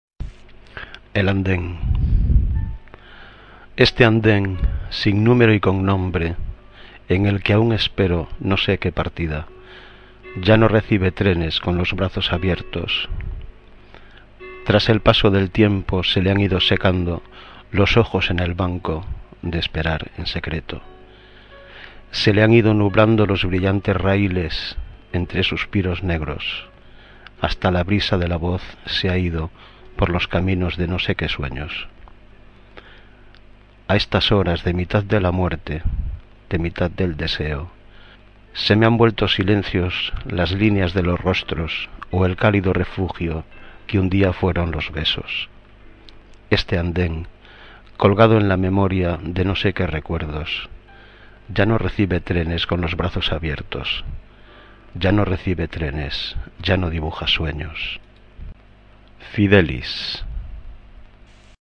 Inicio Multimedia Audiopoemas El andén.